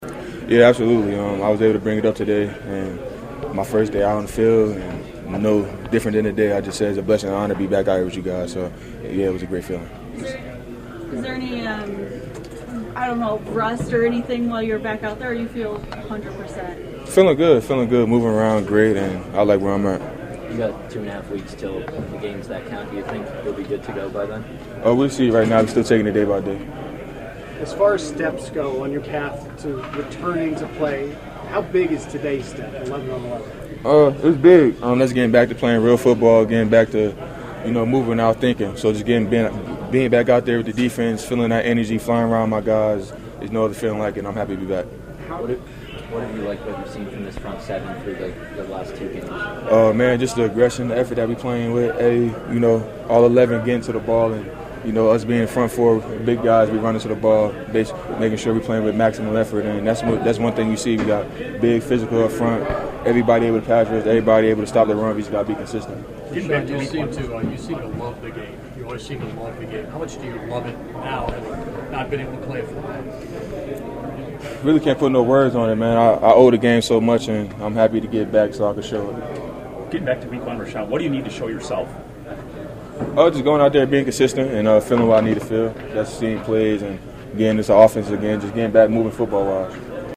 After practice, Gary met reporters and welcomed questions about reaching his latest milestone to recovery.